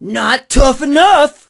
pam_kill_vo_03.ogg